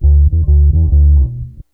BASS 38.wav